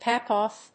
アクセントpáck óff